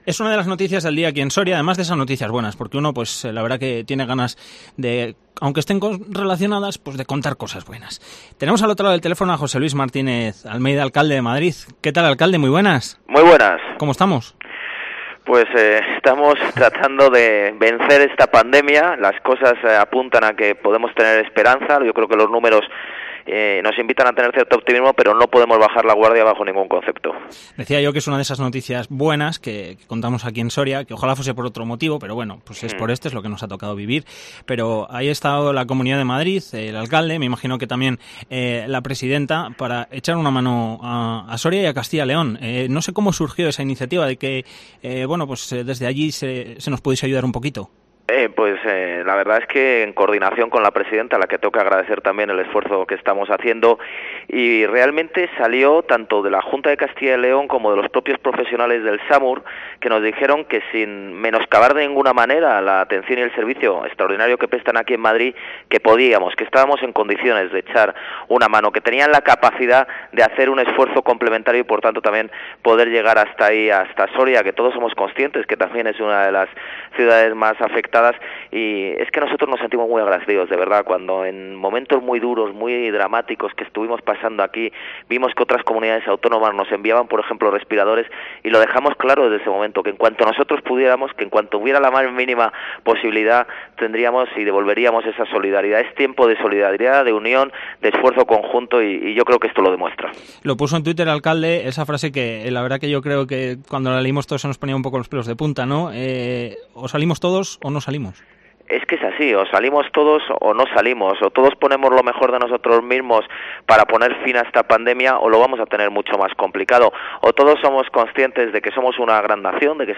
AUDIO: El alcalde de Madrid hablaba en Cope Soria sobre la ayuda que Madrid trae hasta Soria,